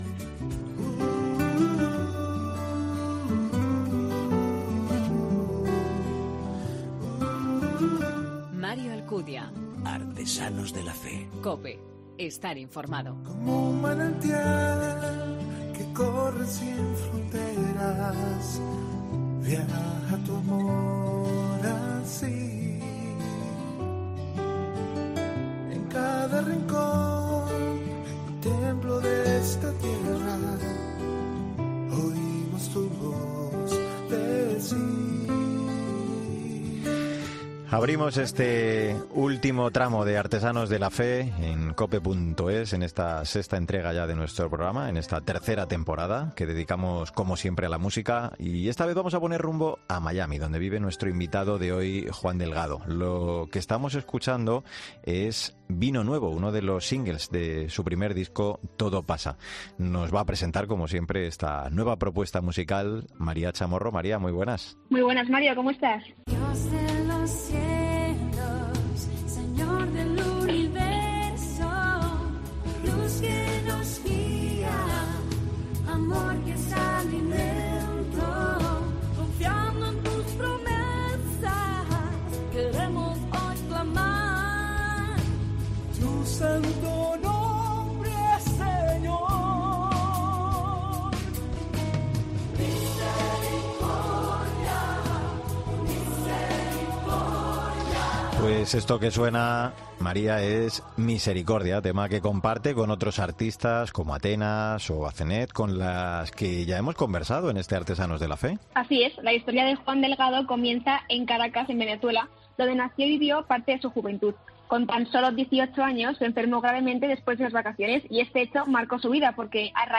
En la entrevista hemos hablado de su disco “Todo pasa”, un trabajo inspirado en Santa Teresa de Jesús.